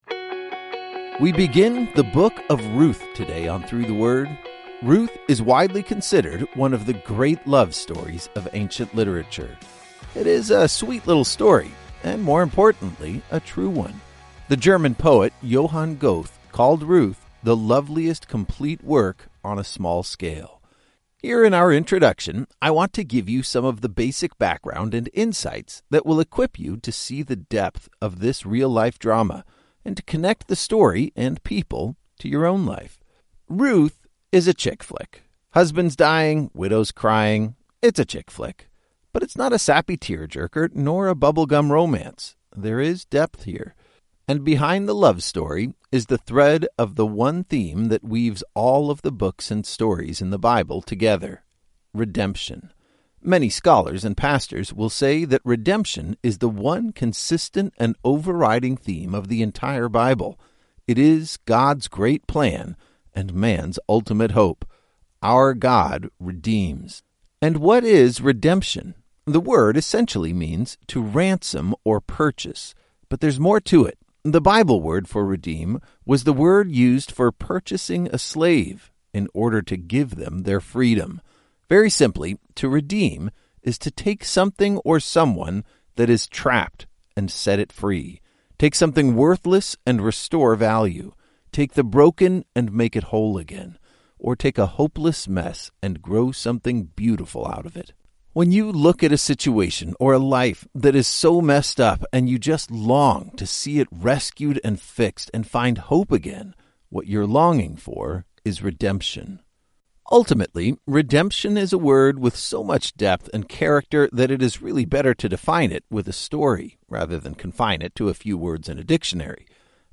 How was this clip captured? Through the Word walks you through each chapter of Ruth with clear and concise audio that will help you follow the drama and uncover the subtle beauty of this classic story.